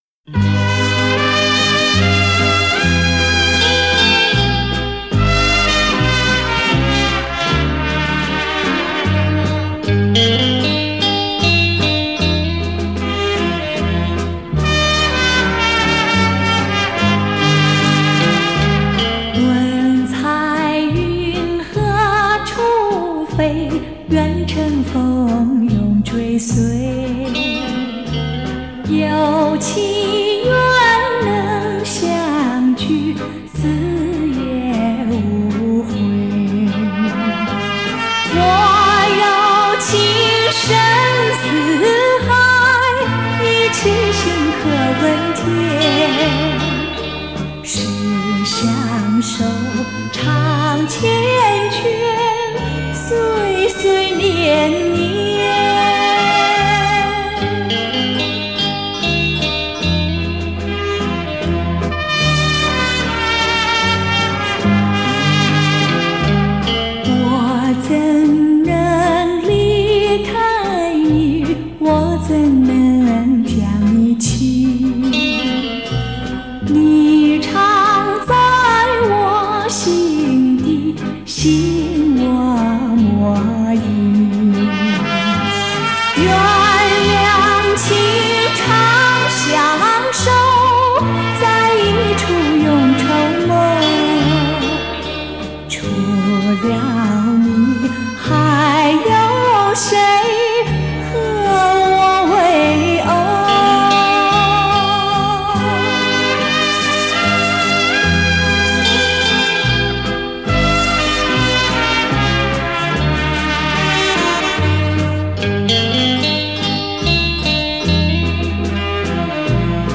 CD2 - 福建名曲